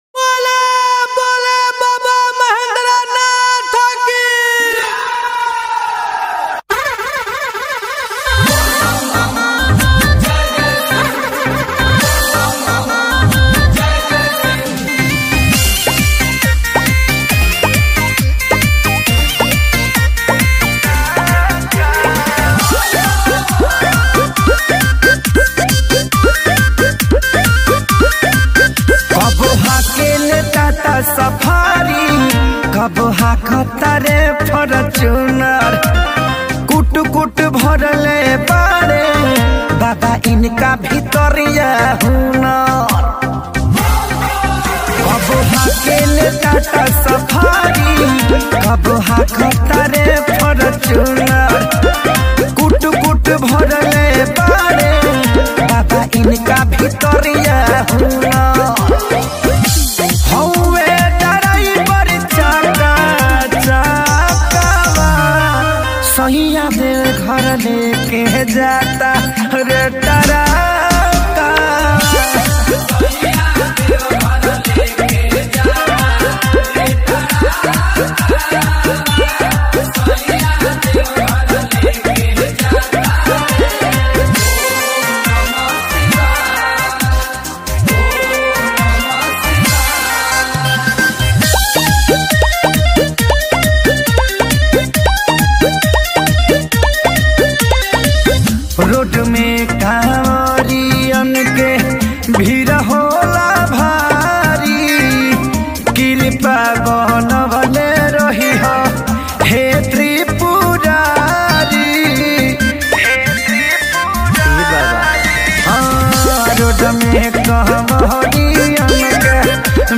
New Bhojpuri Bolbam song 2022